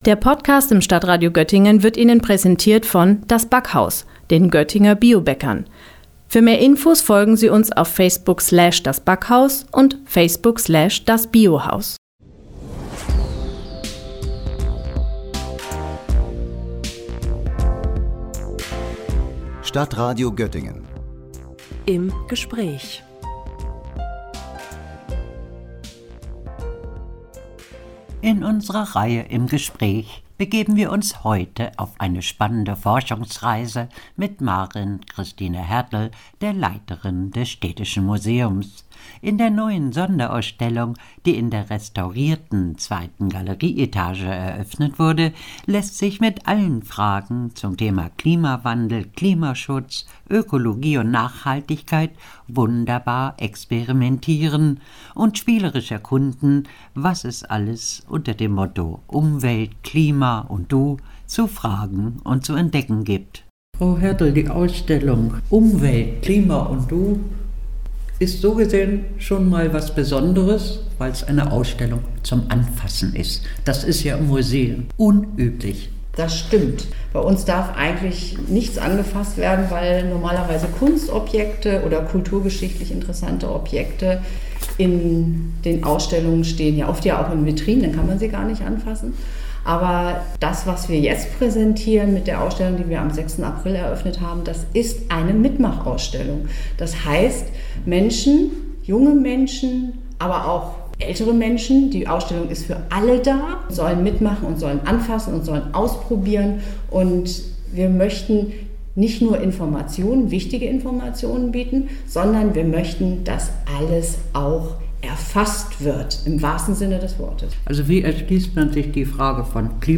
„Umwelt, Klima und Du“ – Gespräch über die interaktive Ausstellung im Städtischen Museum